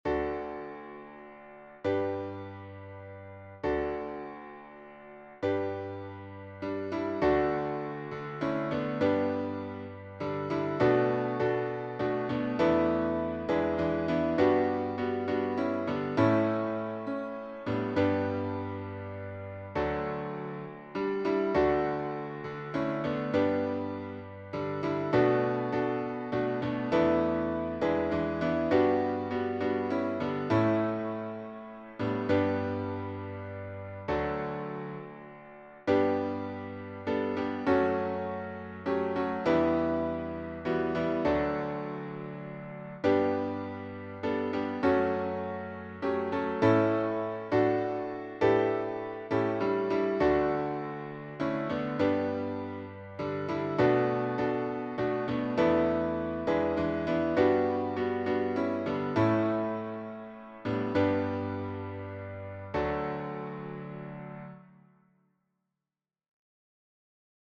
Hymn Composer
SATB
Accompaniment